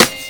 Banks SD2.wav